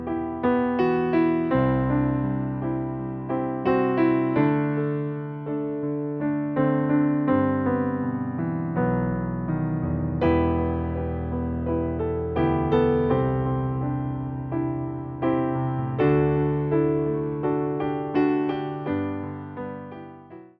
Wersja demonstracyjna:
42 BPM
e – moll